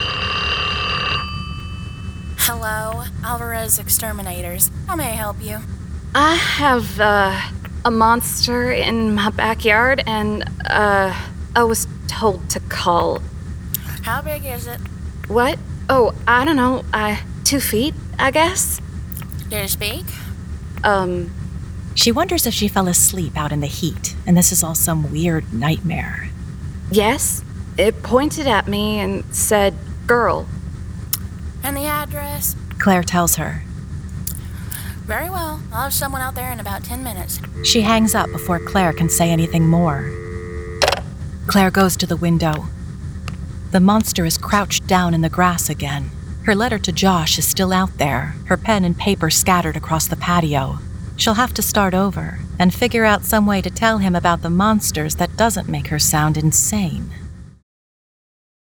Full Cast. Cinematic Music. Sound Effects.
[Dramatized Adaptation]
Genre: Mystery